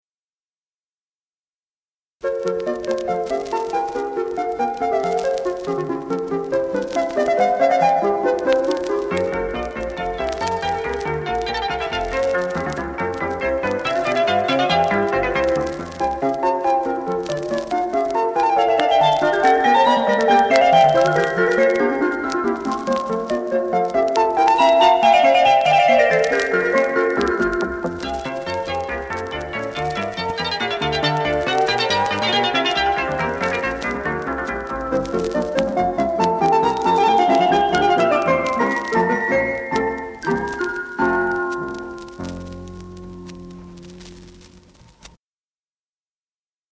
Virtuoso electronic performances